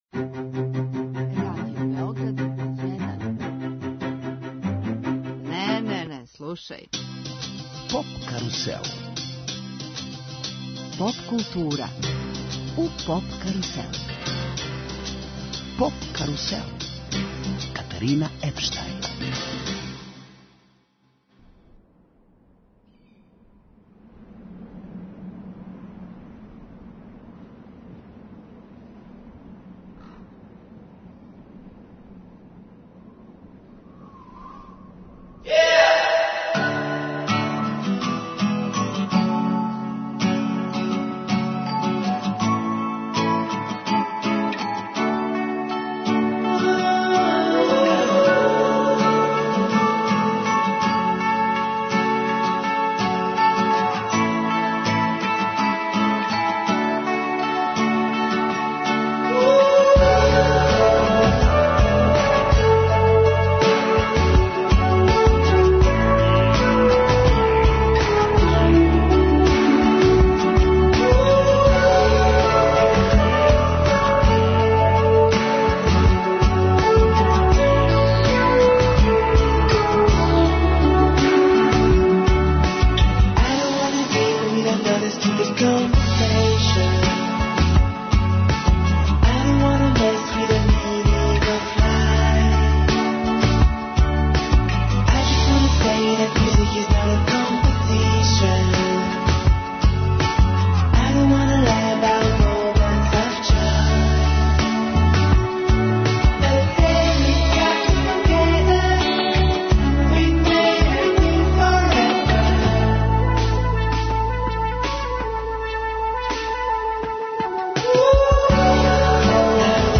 Емитујемо директан програм са највеће европске музичке конференције Еуросоник, која се одржава у Гронингену (Холандија).